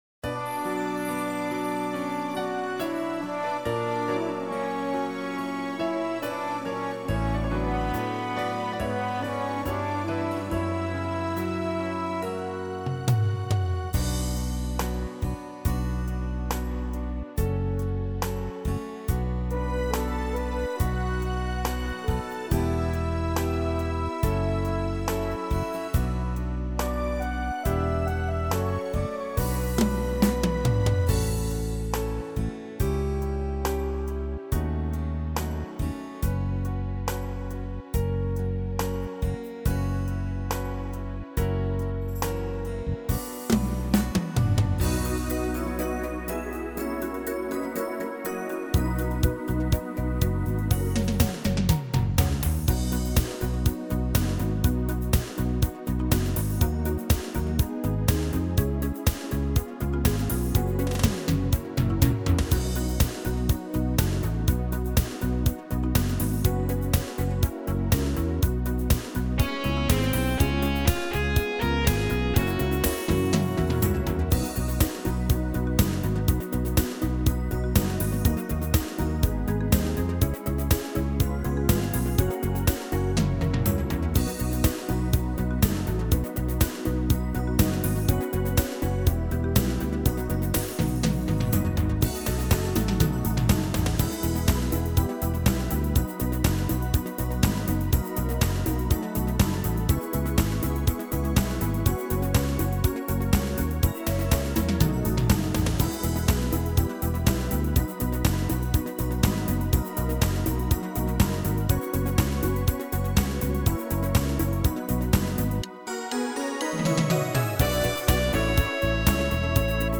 •   Beat  03.